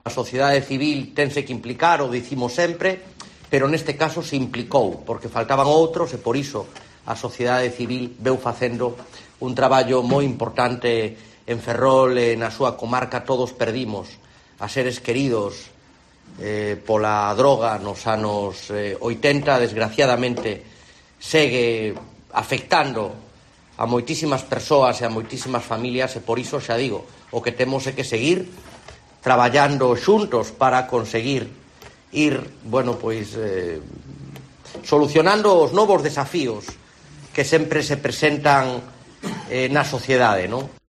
Participantes en la reinauguración de las instalaciones y sonido de Rey Varela - FOTO: Diputación